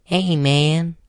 描述：我用书呆子的声音说嘿，伙计。